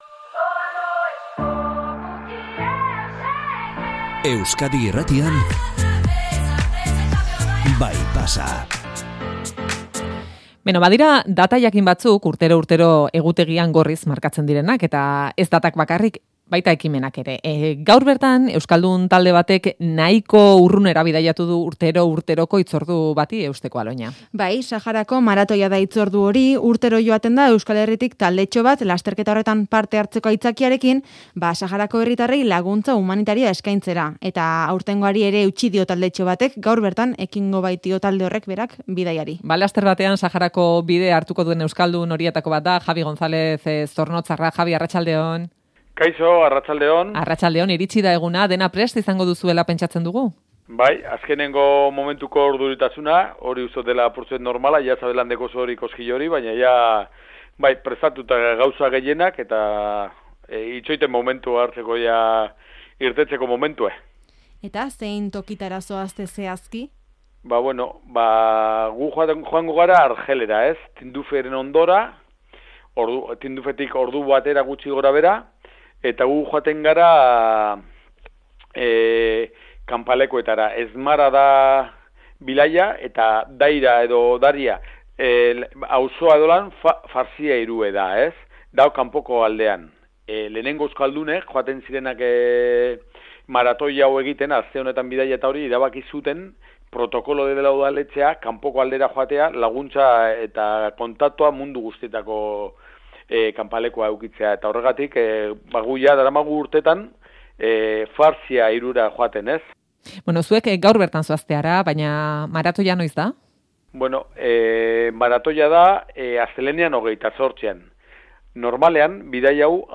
eta aireratu aurretik berarekin hitz egin dugu.